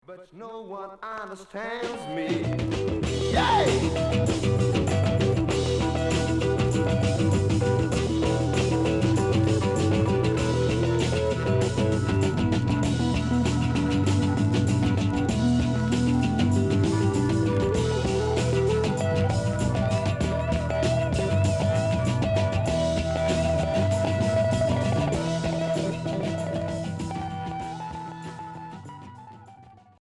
Groove psychédélique Unique 45t retour à l'accueil